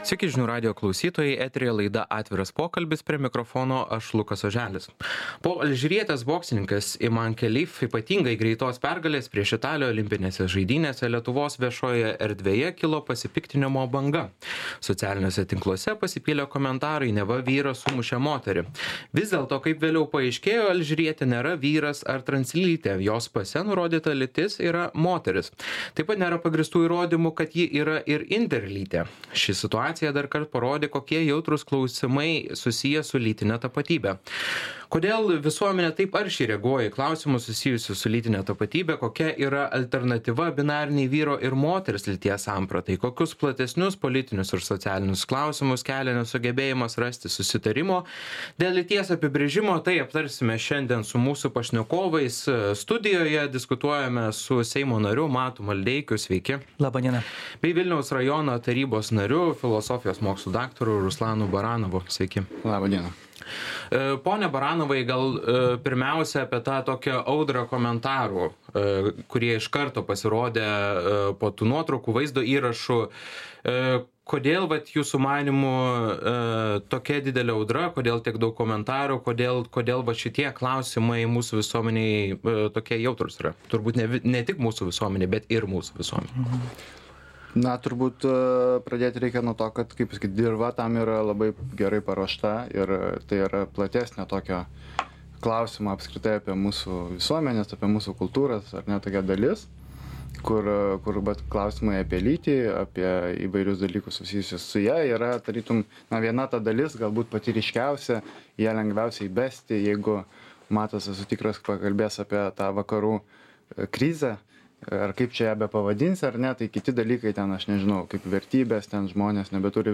Apie tai diskutuosime su Seimo nariu Matu Maldeikiu bei Vilniaus rajono tarybos nariu, filosofijos mokslų daktaru Ruslanu Baranovu.